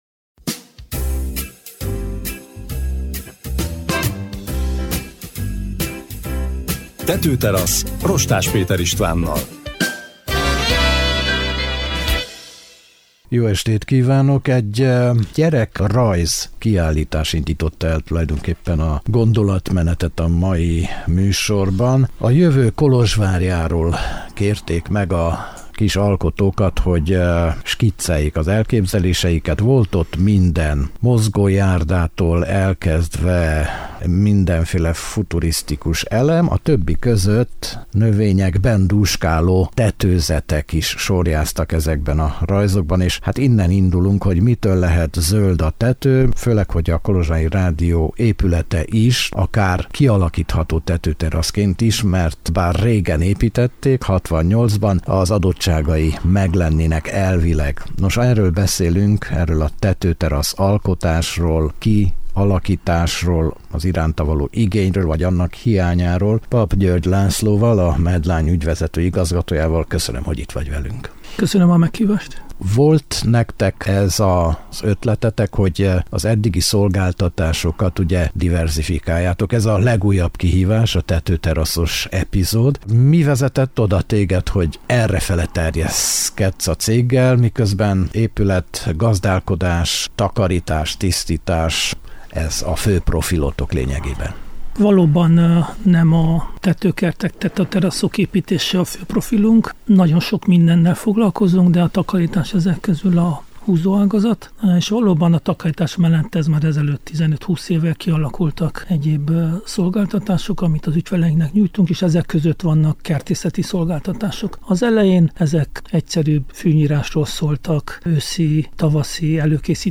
Hatványozottan emelkedett hangulat, zöldítés urbánus környezetben a luxus és az új trend között félúton. És a műsor, ahol a meghívott is tesz fel kérdést, nem is egyet.